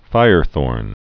(fīrthôrn)